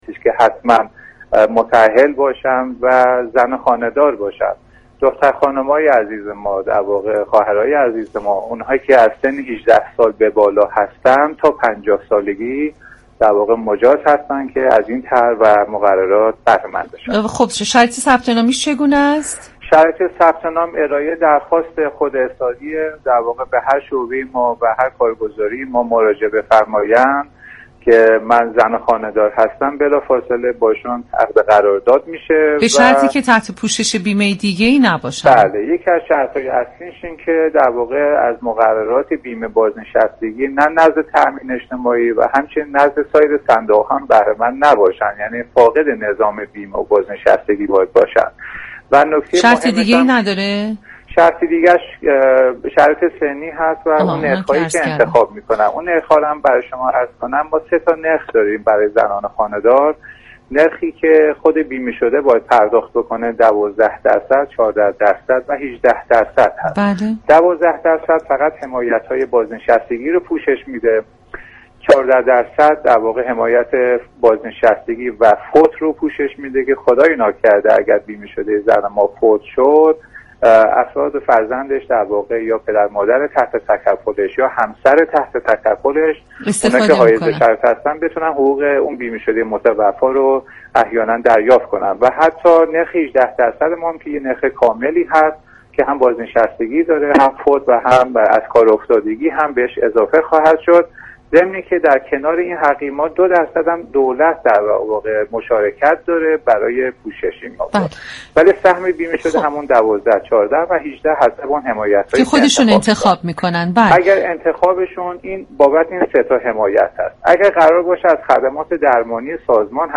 معاون سازمان تامین اجتماعی در گفتگو با دال رادیوجوان با اشاره به وظیفه تامین و توسعه پوشش بیمه ای برای همه گروه های جامعه گفت: بیمه زنان خانه دار از سوی تامین اجتماعی در حال گسترش است و تا پایان خرداد ماه بالای 300 هزار نفر از بانوان كشور تحت این حمایت قرار گرفته اند .